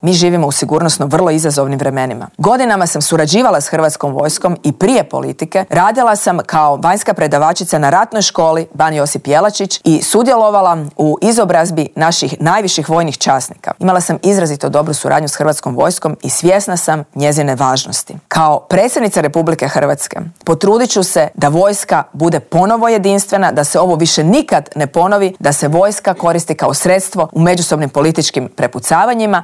U studiju Media servisa svoj izborni program predstavila nam je nezavisna kandidatkinja Marija Selak Raspudić: "Nismo osuđeni na sukob Milanovića i Plenkovića, većina ljudi samo želi normalne ljude na čelu države."